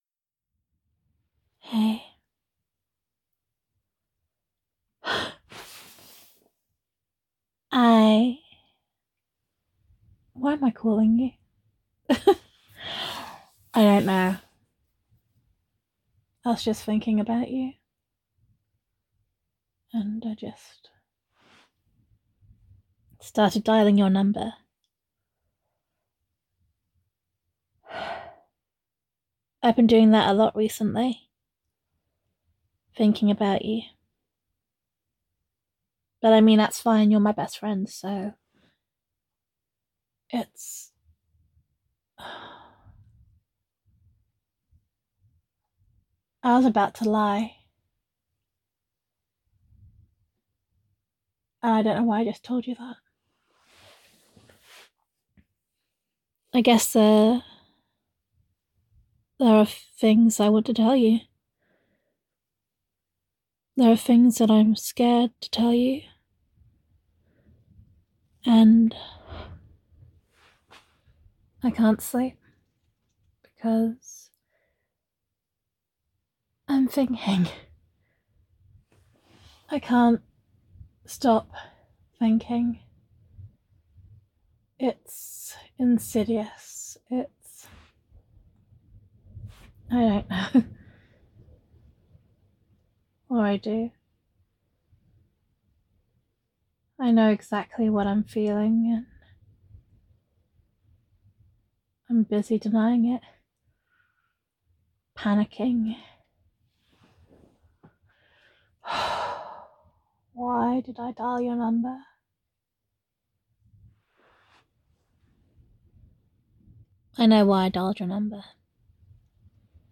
Same voicemail as posted earlier just without the telephone EQ.
[F4A] Why Am I Calling You? [Thinking About You][I Was About to Lie][I Can’t Sleep][Wanting to Tell You Something][Best Friend Roleplay][Telephone EQ][Gender Neutral][Best Friend Voicemail]